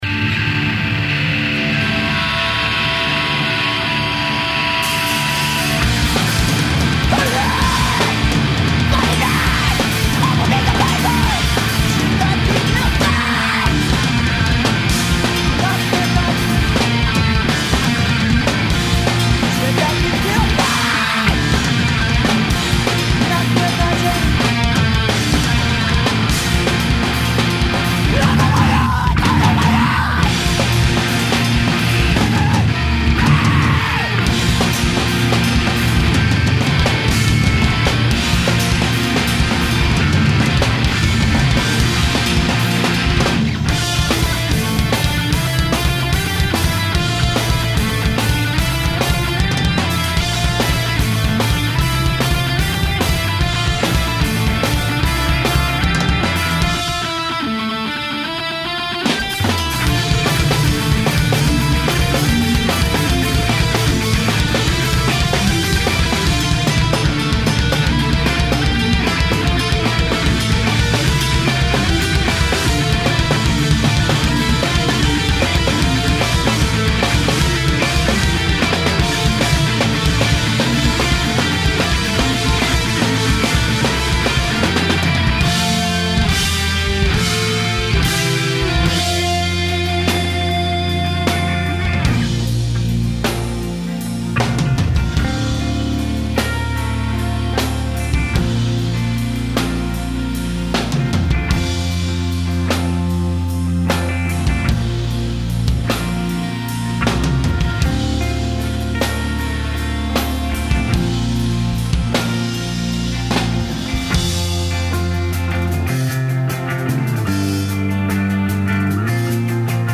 Energie+emotion a l'etat pur.